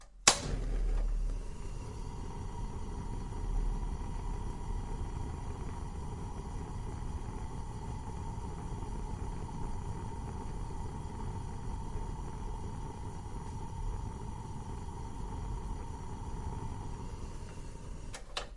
烹饪 " 油炸
描述：炸春卷的立体声录音，加了一点压缩。Rode NT4 > FEL电池前置放大器 > Zoom H2 线路输入.
Tag: 烹饪 深的油炸锅 深-frying 油炸 嘶嘶声 立体声 XY